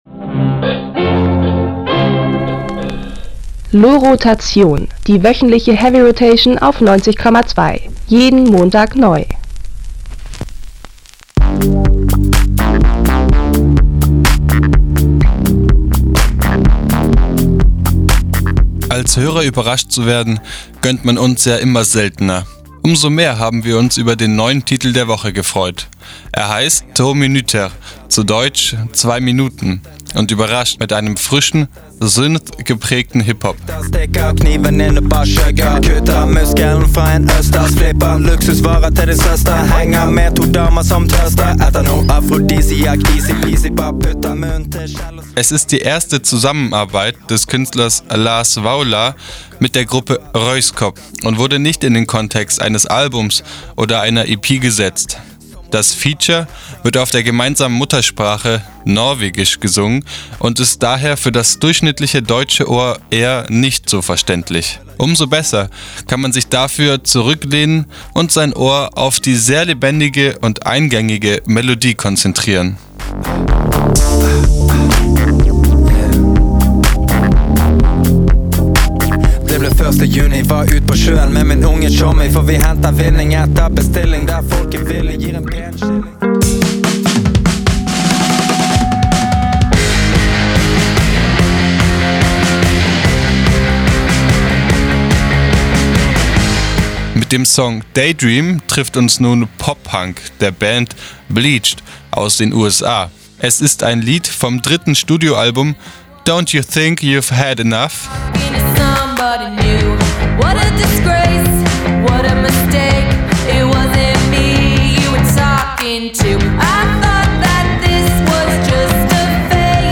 Etwas länger als zwei Minuten, aber gönnt euch trotzdem den Audiobeitrag zur LOHROtation: